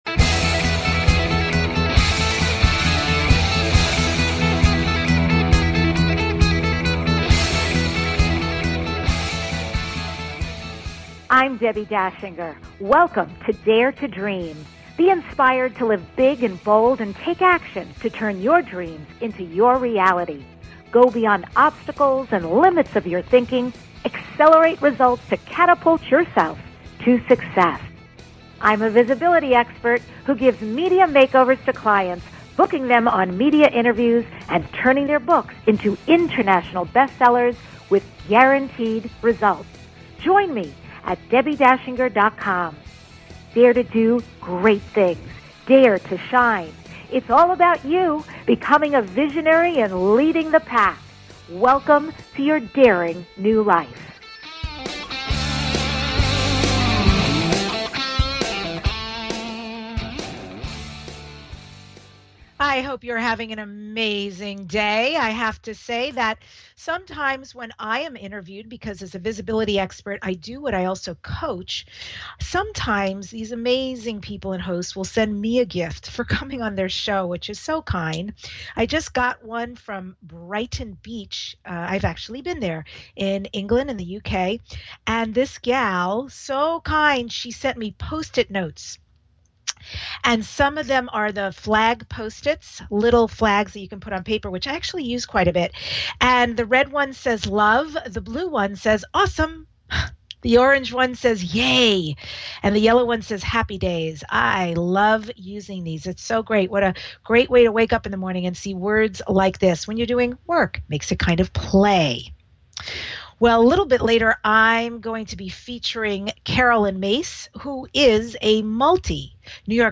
Guest, Caroline Myss